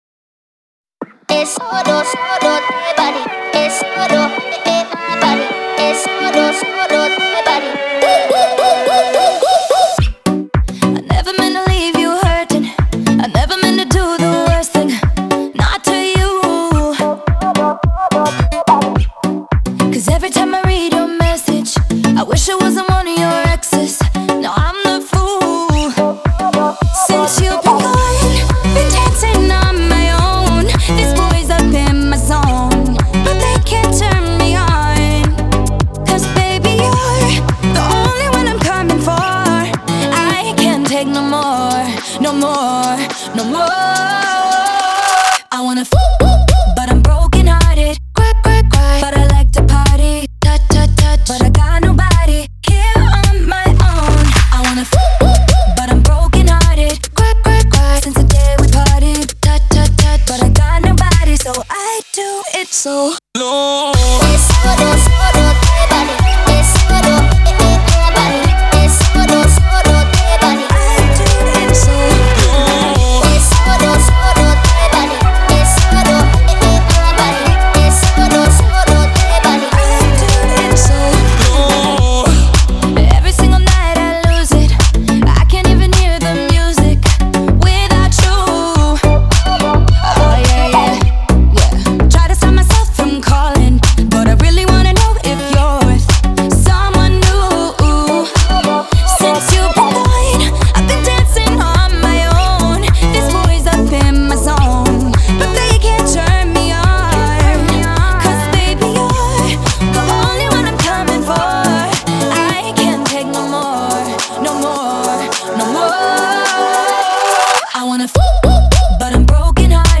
Жанры: поп, электро-поп, поп-рок, R'n'B, дэнс